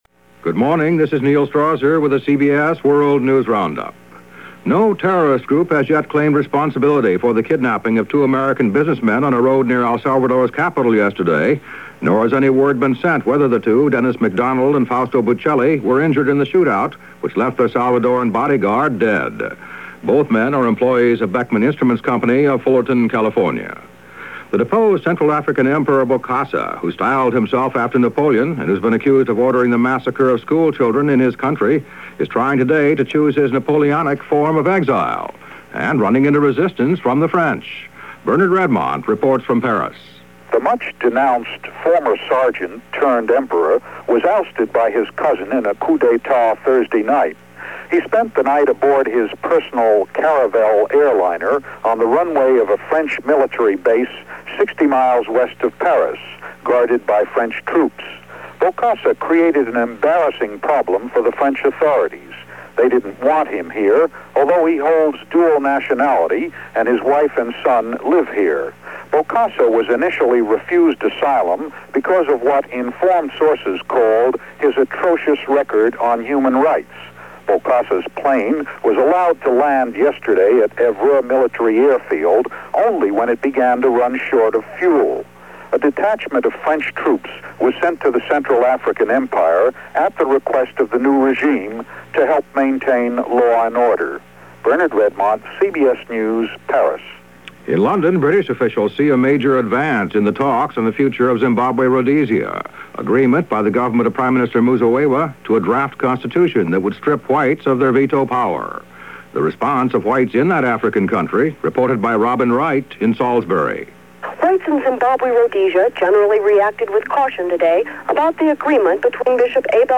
CBS World News Roundup – September 25, 1979 – Gordon Skene Sound Collection